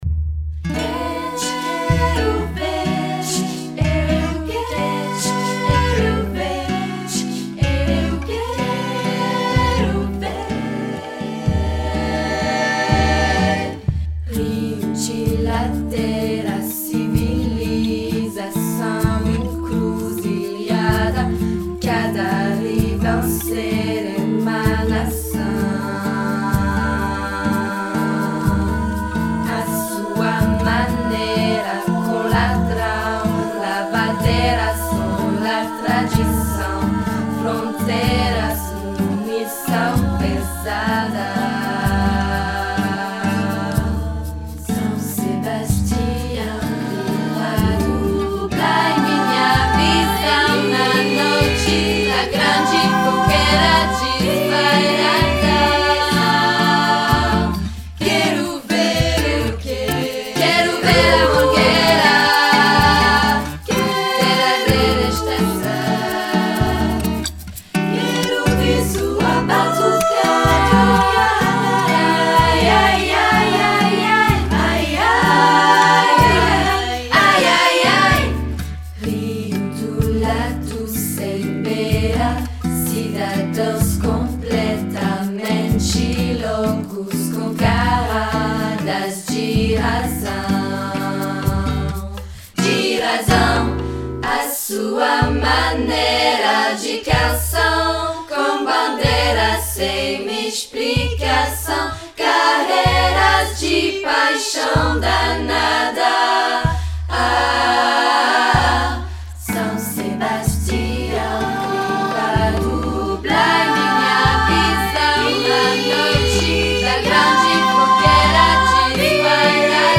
Chants du monde
Musiques du monde